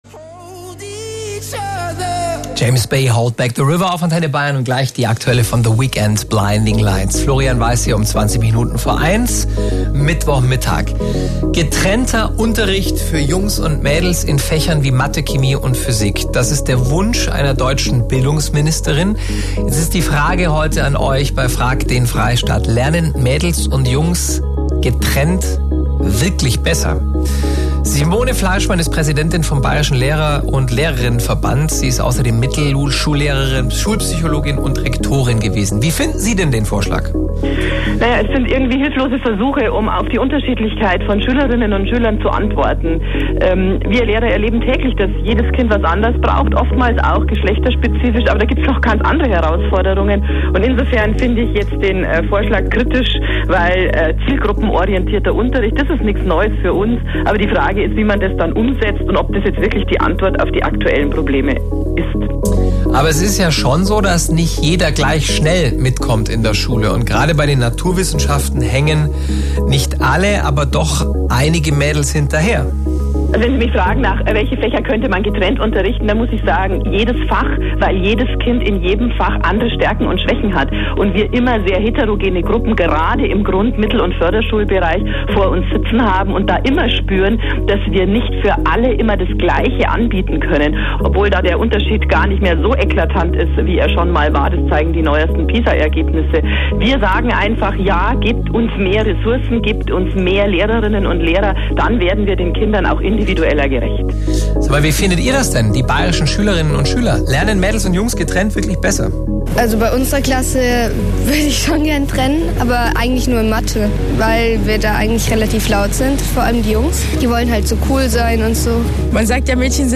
im Interview der Zuhörer-Sendung „Frag den Freistaat“ von Antenne Bayern deutlich: „Das ist ein hilfloser Versuch, auf die Unterschiedlichkeit von Schülerinnen und Schülern zu antworten.